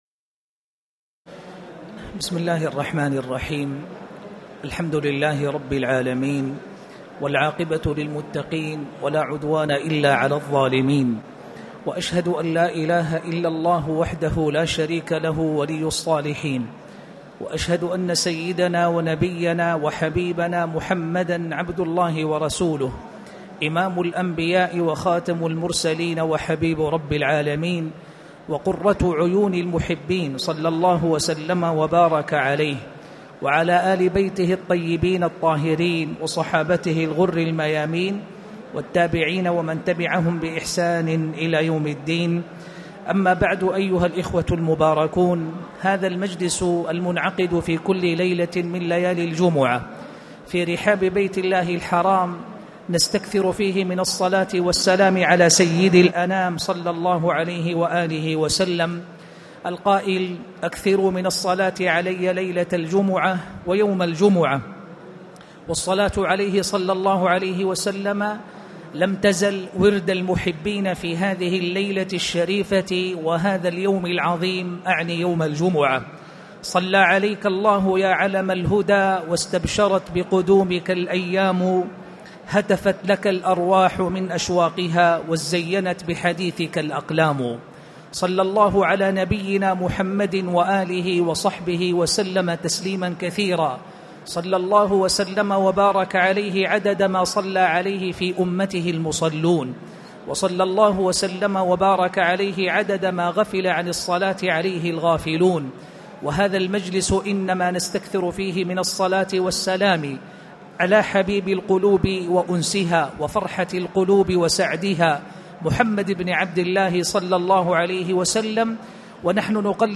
تاريخ النشر ١٥ شعبان ١٤٣٨ هـ المكان: المسجد الحرام الشيخ